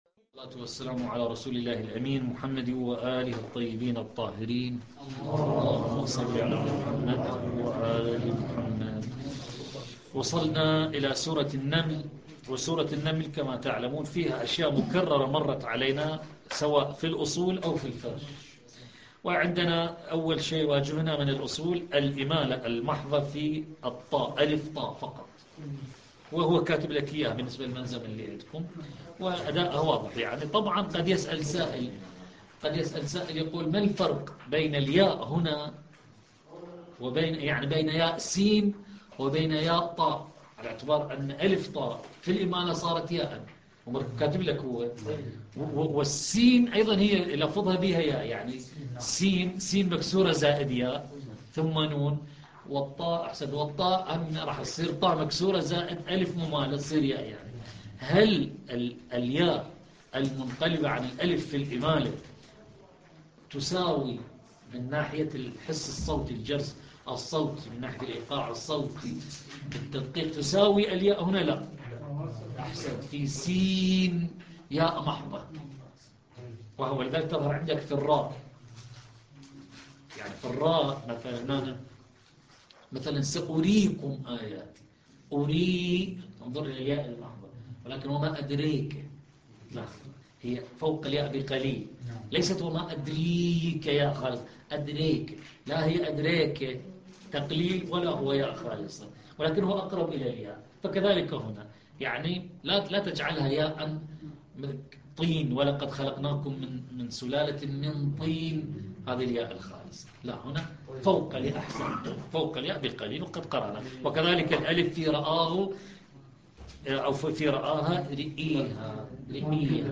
الدرس العشرون - لحفظ الملف في مجلد خاص اضغط بالزر الأيمن هنا ثم اختر (حفظ الهدف باسم - Save Target As) واختر المكان المناسب